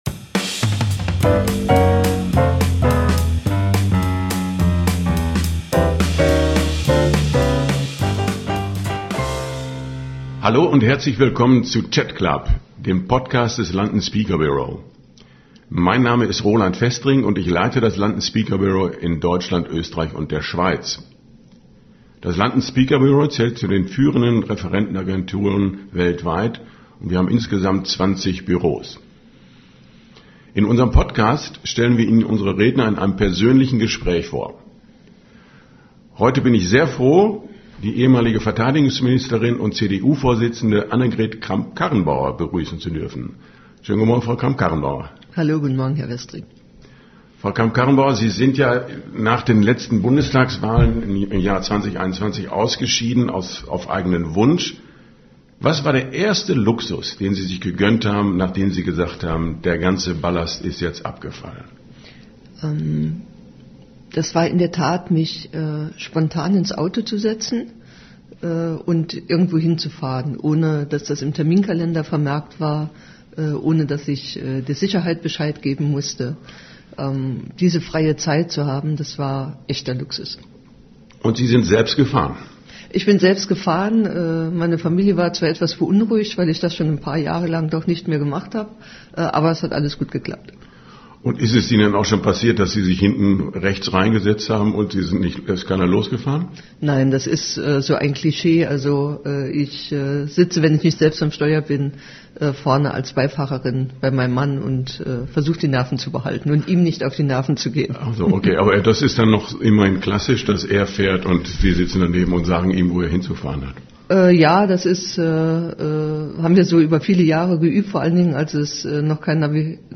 Im Chat Club des London Speaker Bureau wird – ganz in der Tradition der britischen Clubs – das persönliche und tiefgründige Gespräch gepflegt.